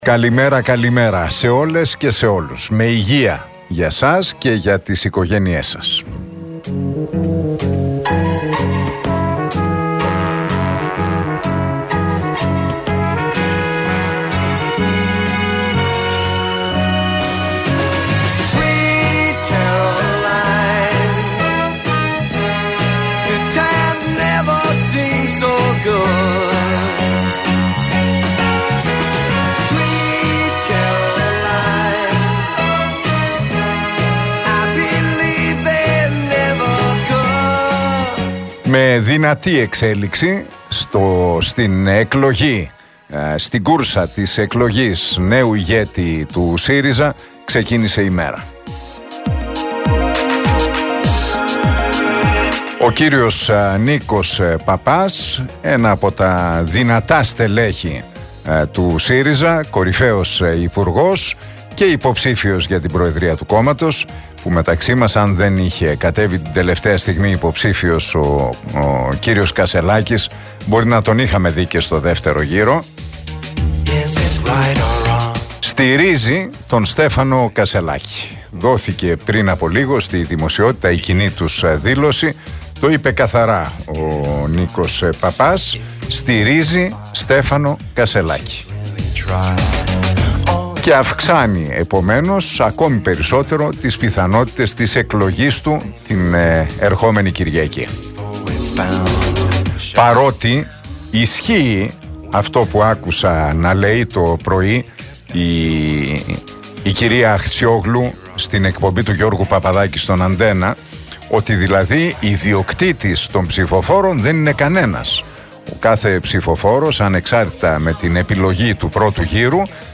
Ακούστε το σχόλιο του Νίκου Χατζηνικολάου στον RealFm 97,8, την Τρίτη 19 Σεπτεμβρίου 2023.